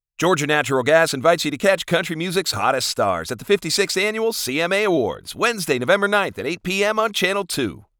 Professional American male voice. Conversational, guy next door, instructional, strong and occasionally snarky!
CMA awards spot for Georgia Natural Gas, tv commercial
Middle Aged